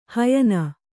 ♪ hayana